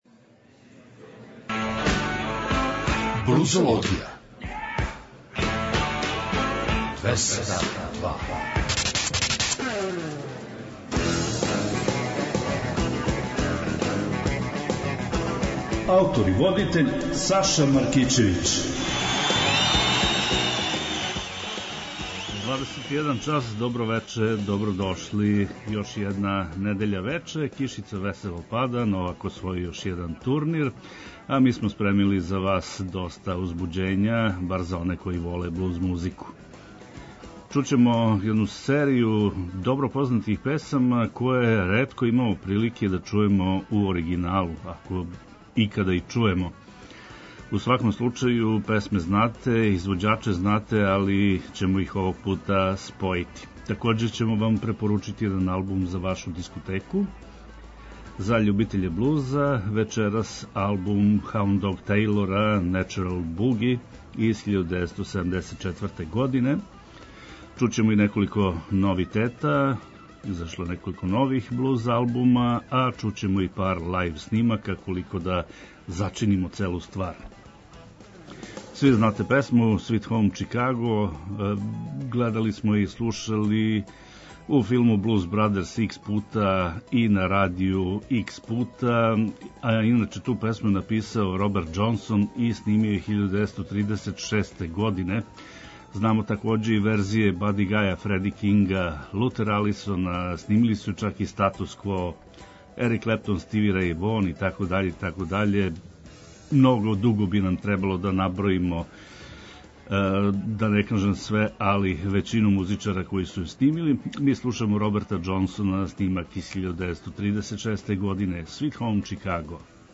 Поштујући захтеве многих слушалаца, Београд 202 је од 10. јануара 2015. покренуо нову музичку емисију под називом „Блузологија“.
Нудимо вам избор нових музичких издања из овог жанра, али не заборављамо ни пионире који су својим радом допринели развоју блуза и инспирисали младе музичаре широм света да се заинтересују и определе за професионалну каријеру у овој области.